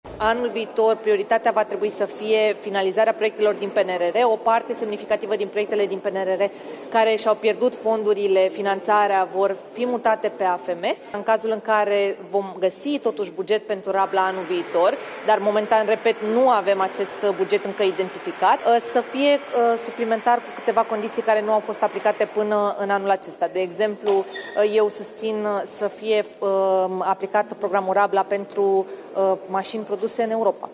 Declarațiile au fost făcute presei la un eveniment organizat de Federaţia Patronală a Energiei.